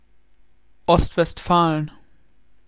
Deutsch: Aussprache von Ostwestfalen , Nordrhein-Westfalen . English: Pronunciation of Ostwestfalen , North Rhine-Westphalia , Germany .